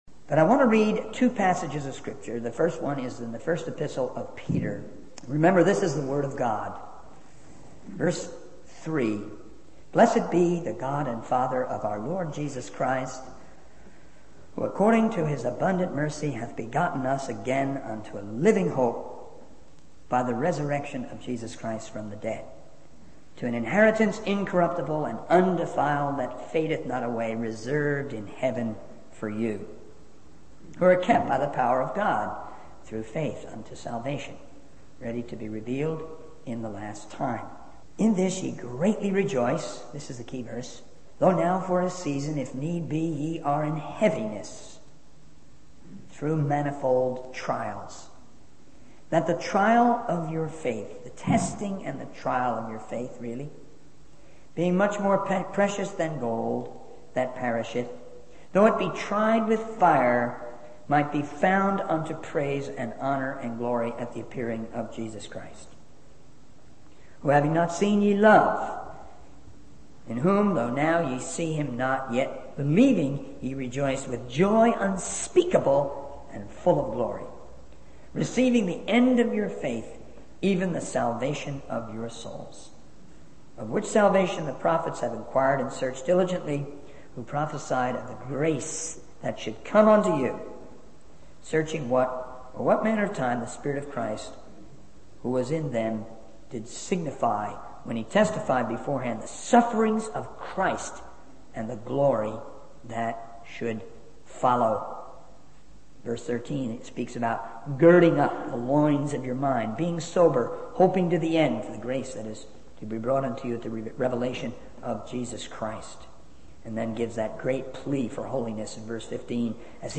In this sermon, the speaker emphasizes the importance of receiving and understanding the word of God.